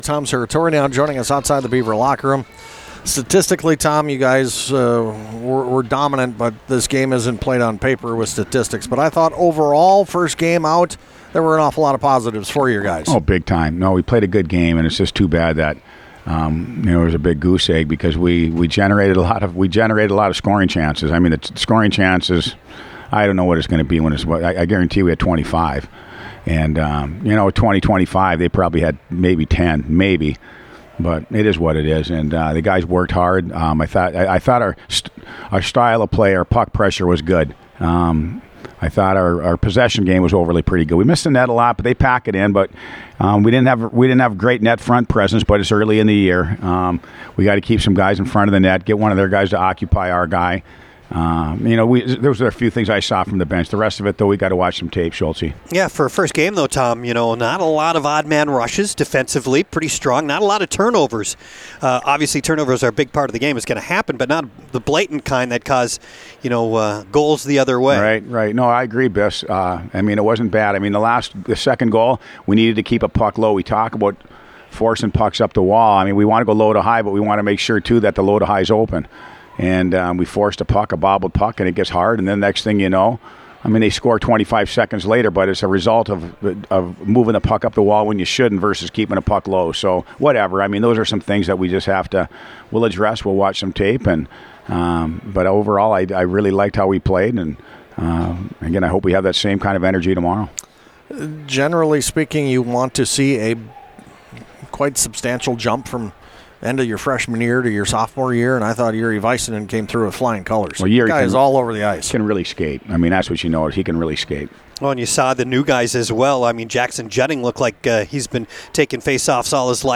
postgame.mp3